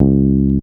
01 RoundWound C#.wav